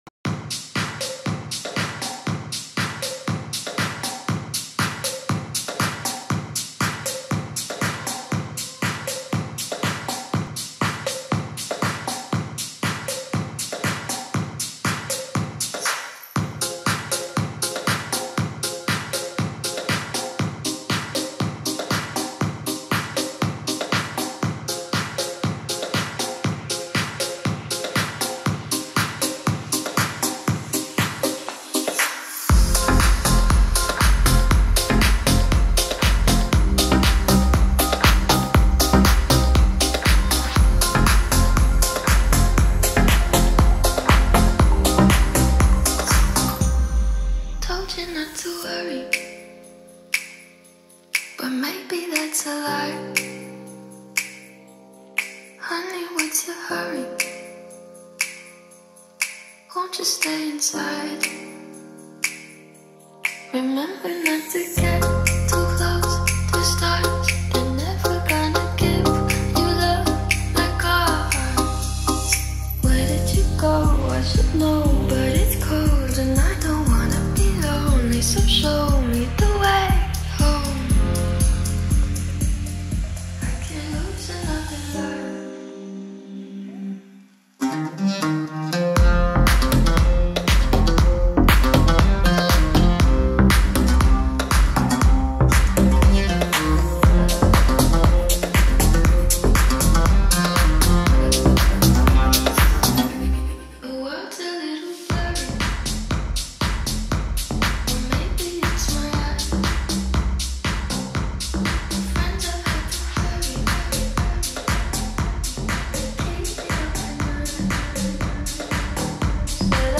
Очень красиво звучит. Понравился переход звуков в наушниках.